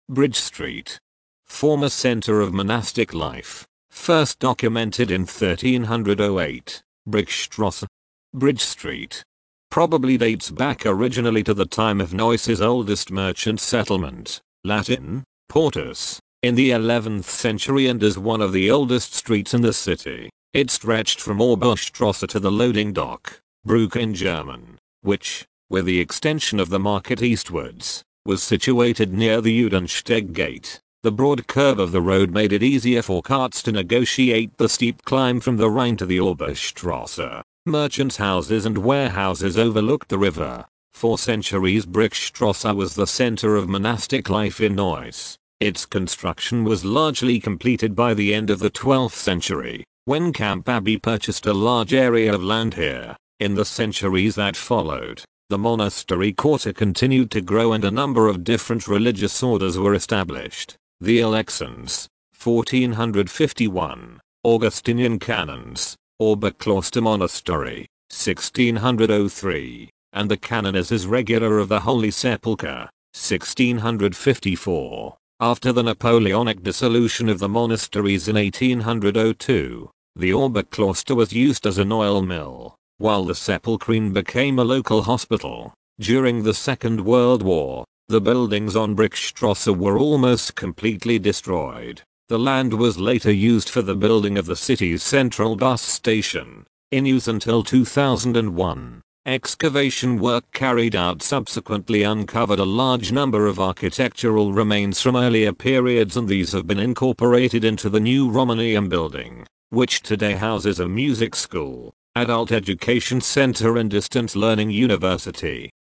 Audio Guide (english)